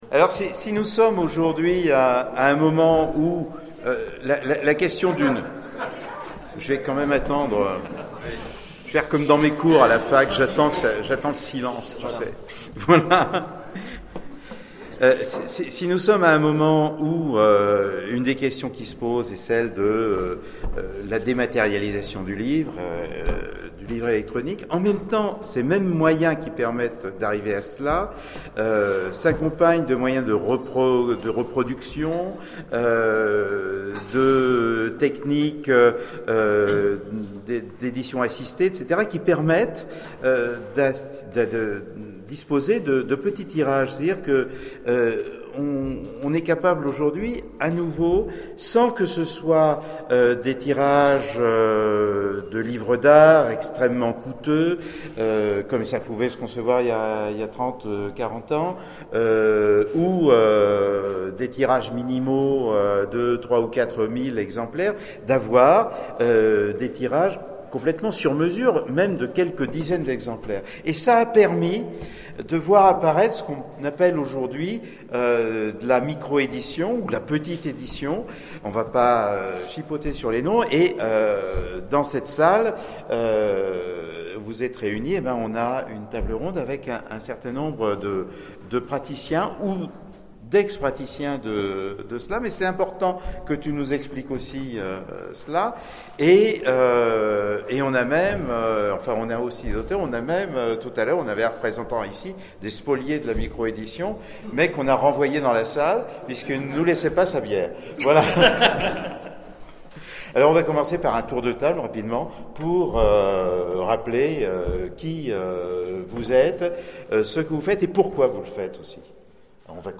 Convention SF d'Aubenas - Débat : Les petits éditeurs : compléments ou concurrents ?